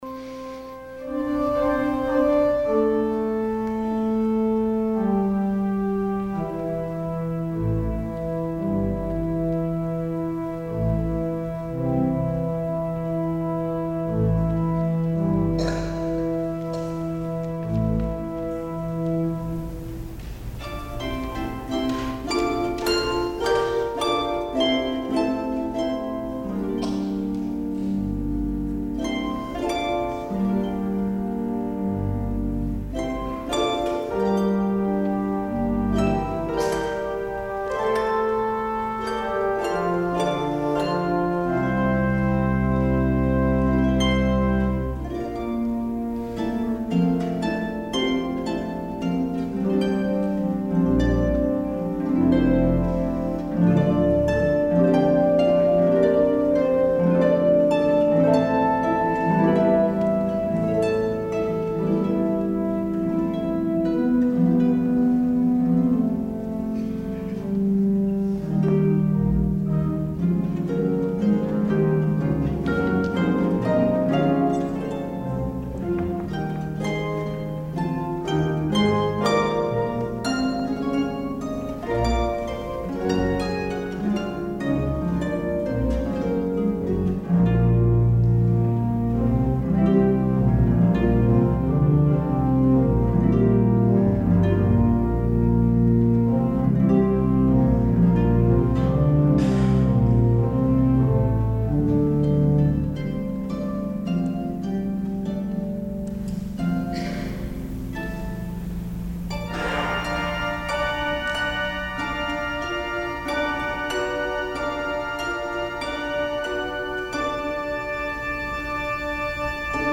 harp
organ